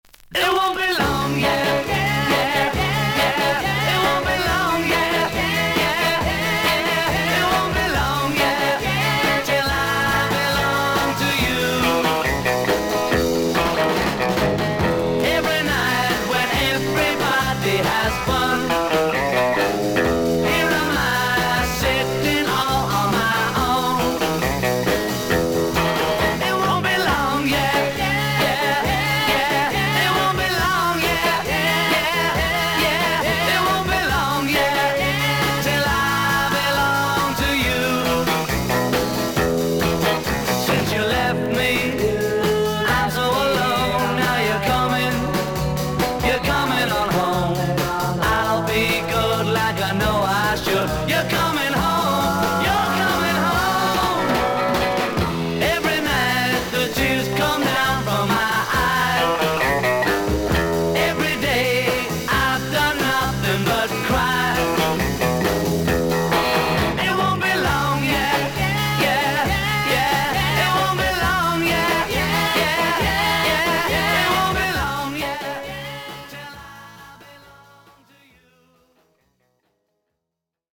少々軽いパチノイズの箇所あり。少々サーフィス・ノイズあり。クリアな音です。